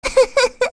Rehartna-Vox_Happy1.wav